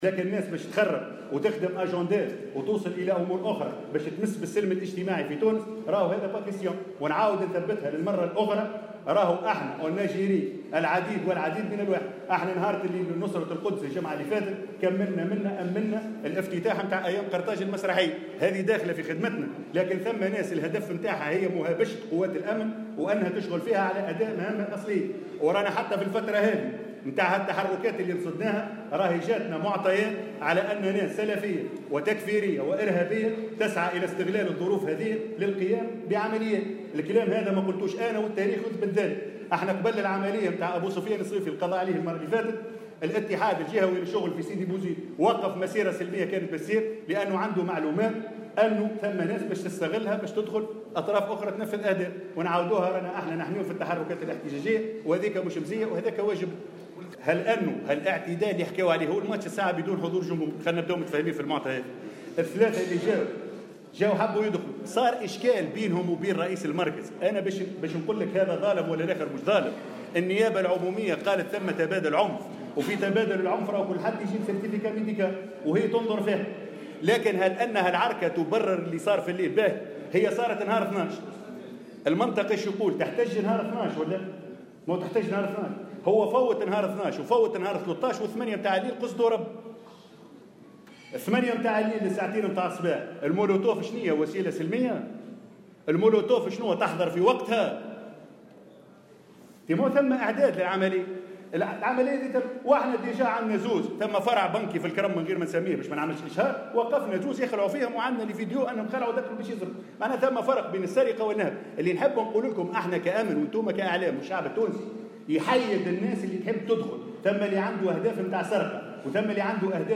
وقال الشيباني في تصريح لمراسل "الجوهرة أف أم" على هامش ندوة صحفية عقدتها الوزارة اليوم، إنه لا تسامح مع كل من يعمد إلى الإضرار بالممتلكات العامة والخاصة، وأن الاحتجاج حق مكفول في الدستور شريطة أن يكون سلميا.